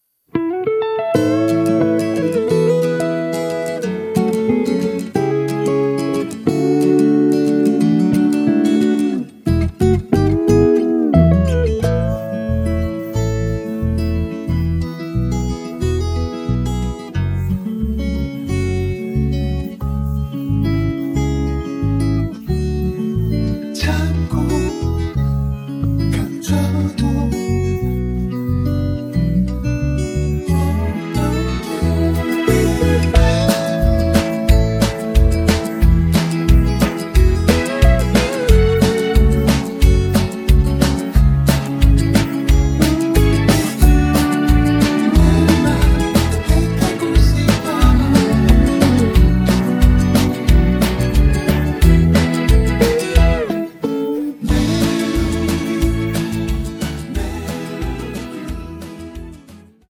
음정 -1키 3:48
장르 가요 구분 Voice MR
보이스 MR은 가이드 보컬이 포함되어 있어 유용합니다.